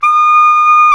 SAX SOPMFD0U.wav